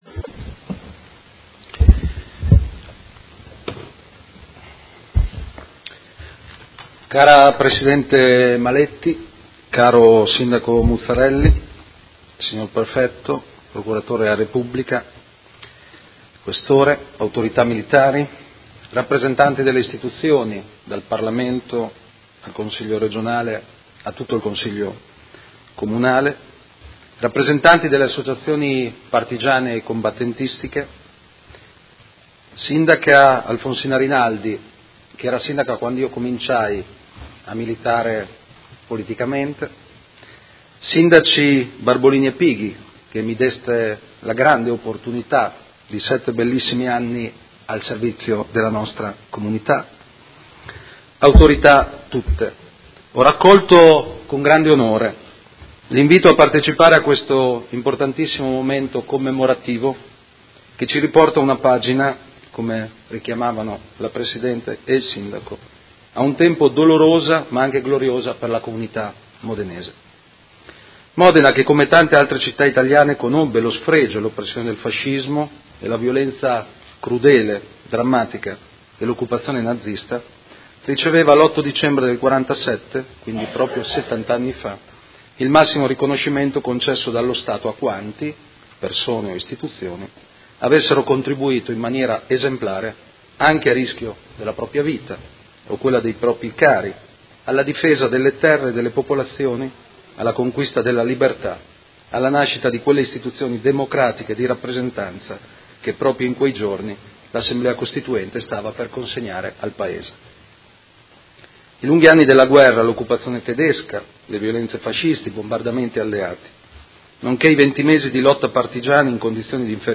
Seduta del 08/12/2017 Modena Medaglia d'oro al Valor Militare. 70° anniversario.